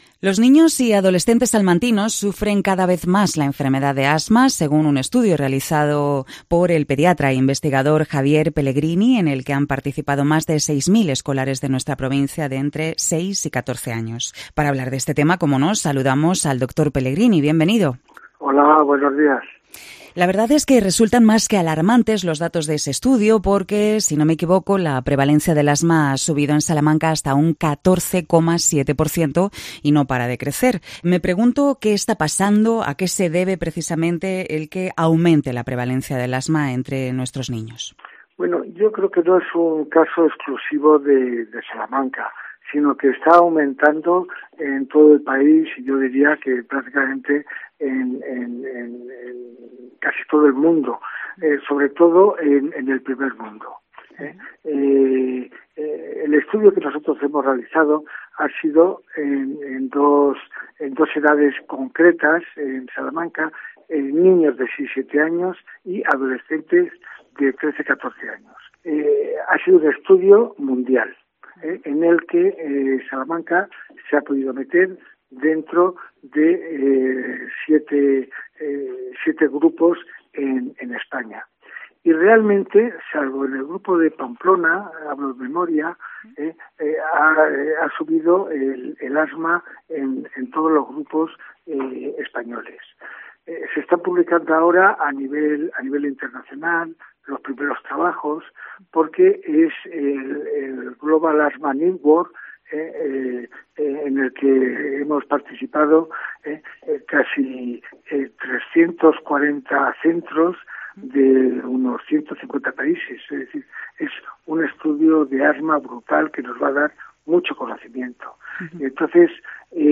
Cope Salamanca entrevista